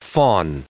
339_fawn.ogg